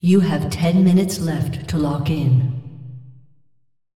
vo-anncr-fem1-tournaments-lockin-10min-01.ogg